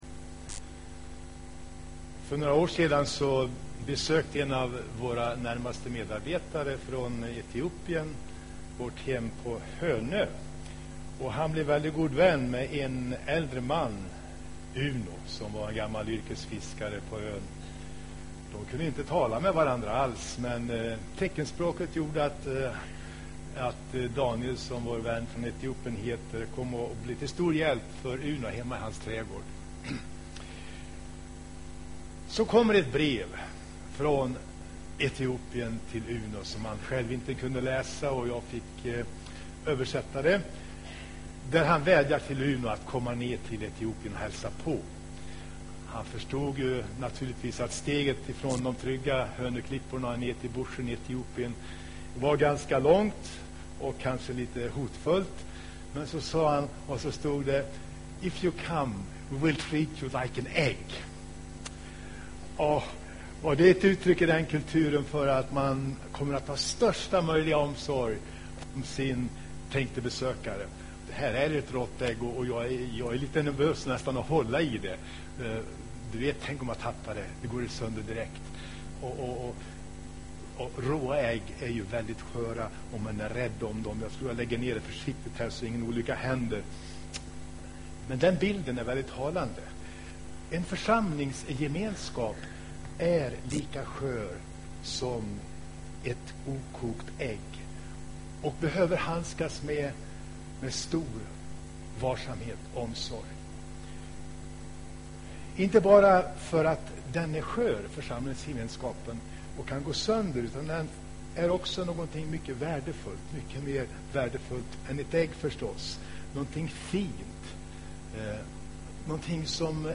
Inspelad i Hagakyrkan, Sundsvall 2011-03-20.